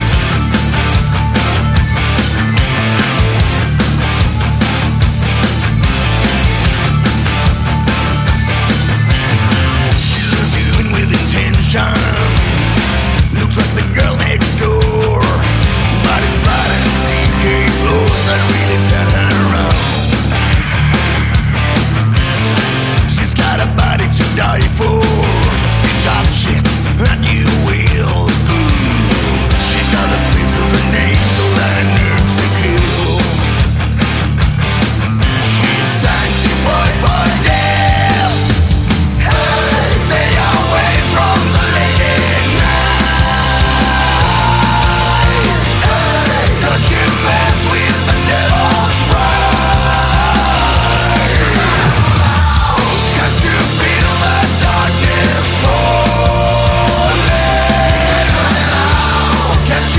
Metal
яркий карнавальный рок